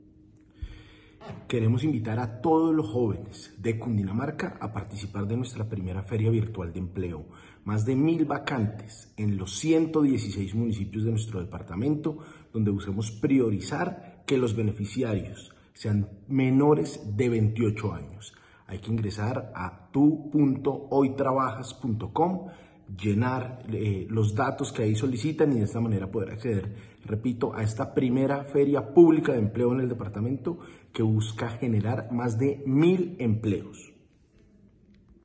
Invitación del gobernador de Cundinamarca, Nicolás García Bustos.